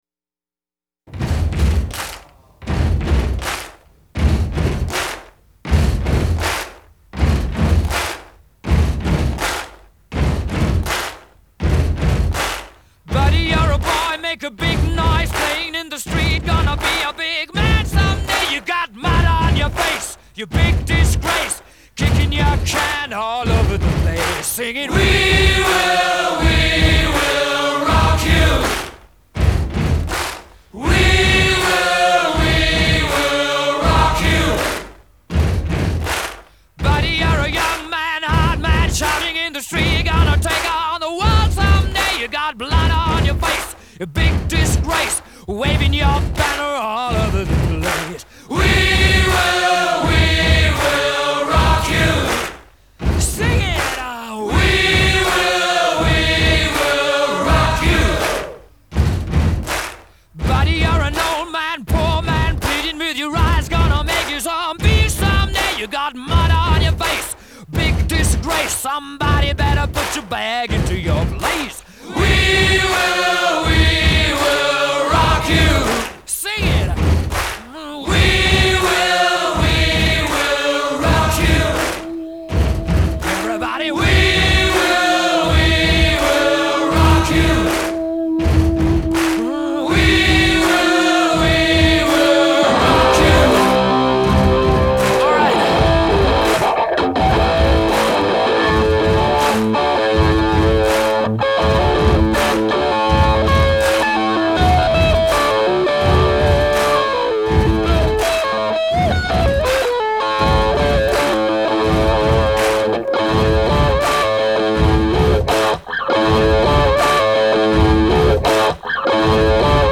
那种节奏让人热血澎湃，让人充满希望。